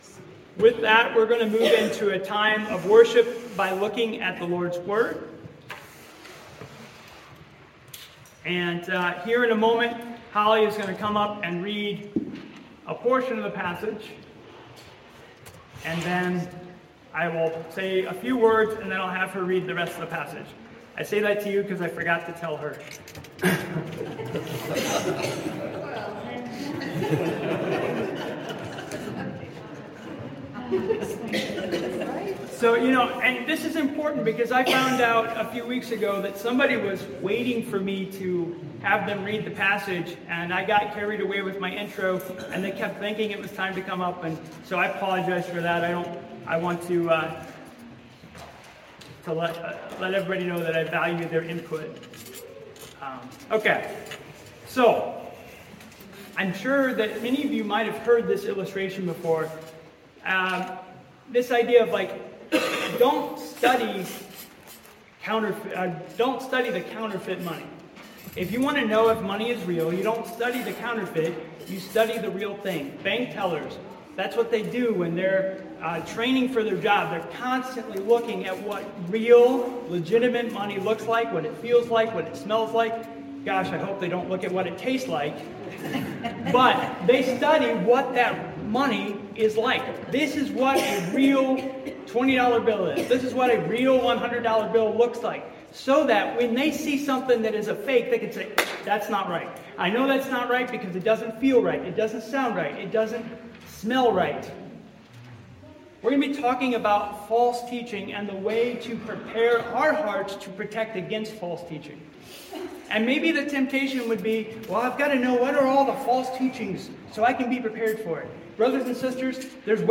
Due to the large wind storm the day before, our church was without power this past Sunday. Although without eletricity and heat, our church service continued as scheduled.